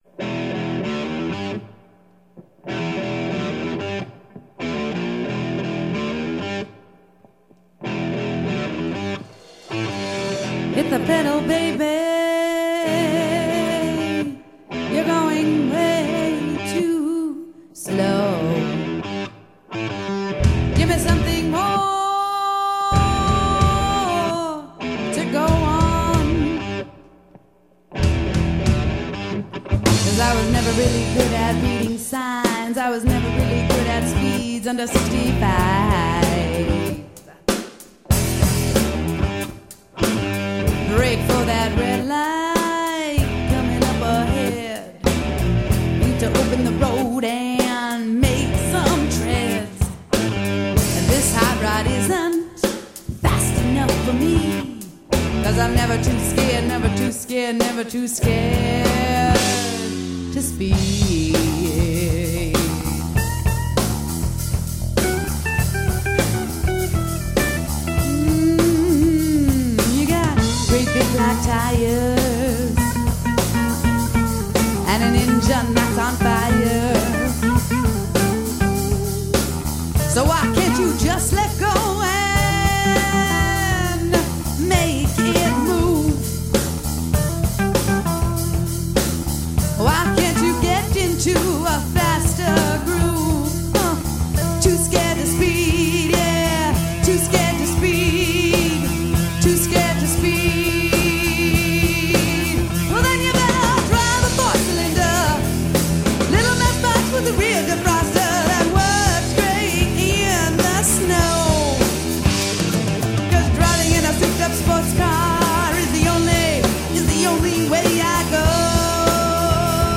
vocals
guitar and vocals
bass guitar
drums/percussion